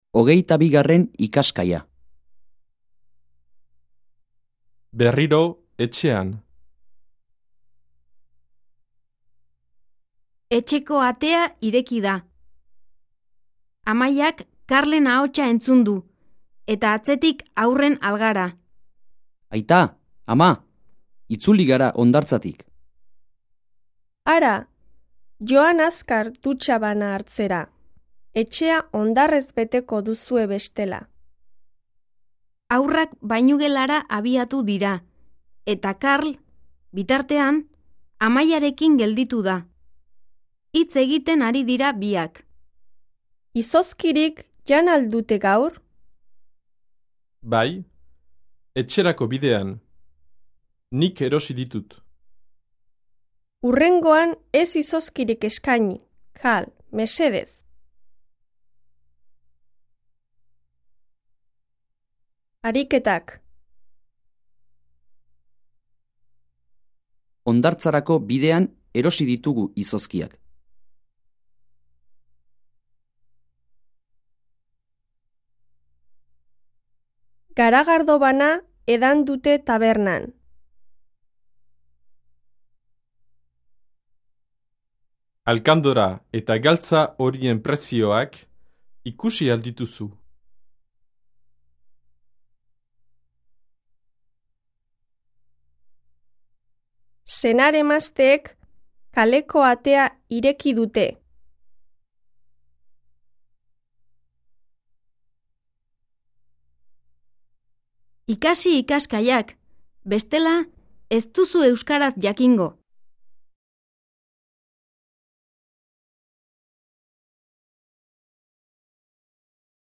Диалог